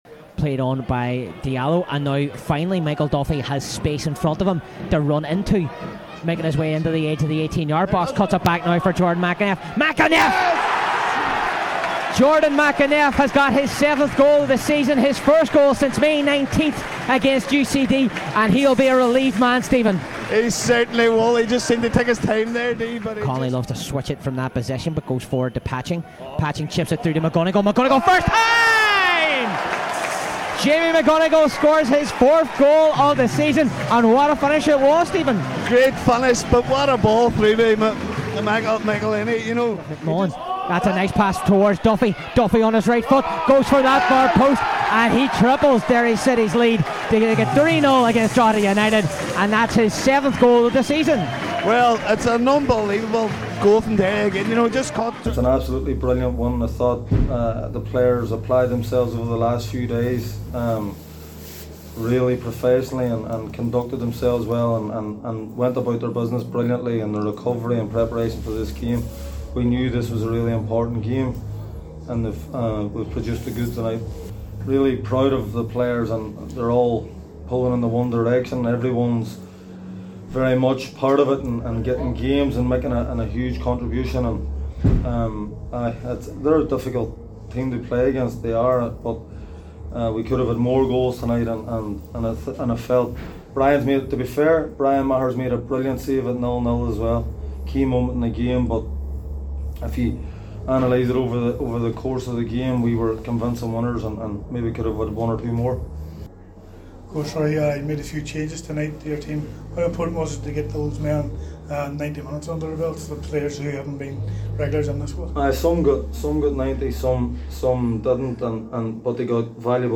after the final whistle Commentary